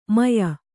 ♪ maya